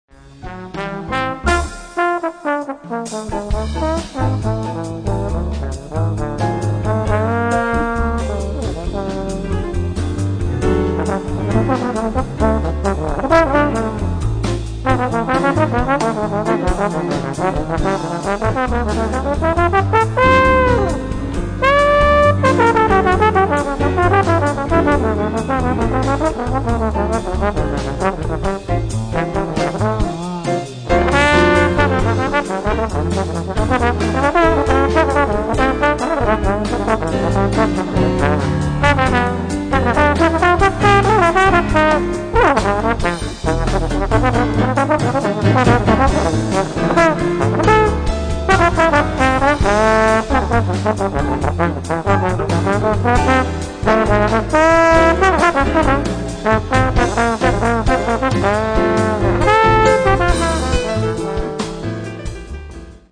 trombone
piano/organ
bass/bass guitar
drums
Recorded at NRK Studio 20, October 19th, 20th 1998.